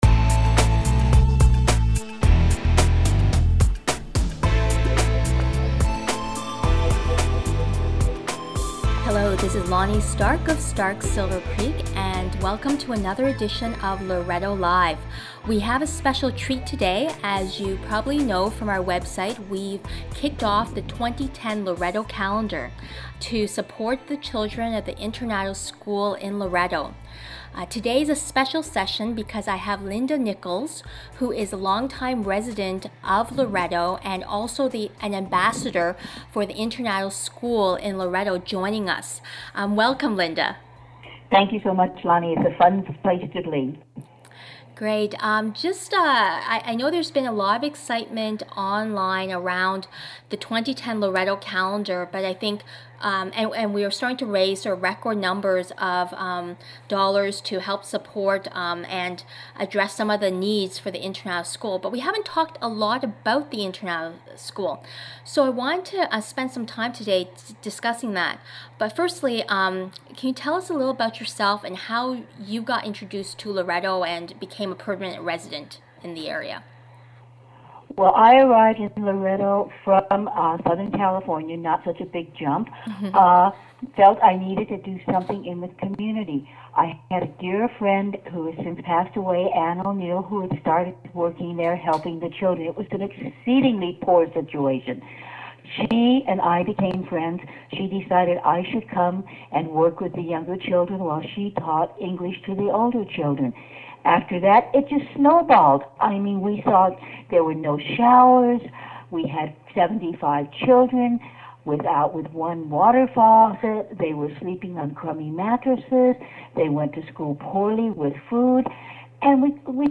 loretolive-internado.mp3